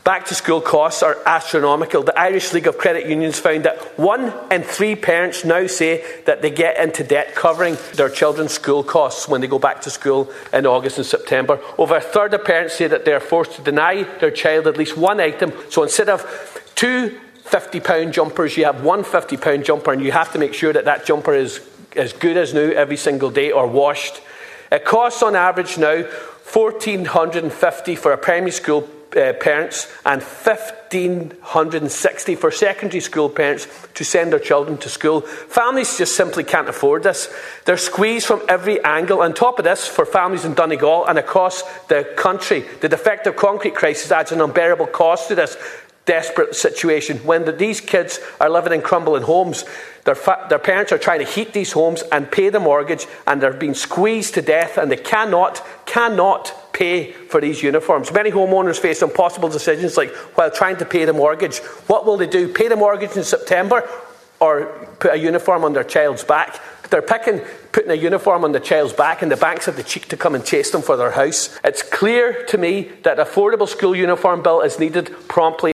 A Donegal TD has told the Dail that Donegal families in defective concrete homes are struggling to pay for school uniforms due to the ‘unbearable cost’ of the defective concrete crisis, as well as the ‘desperate cost of living crisis’.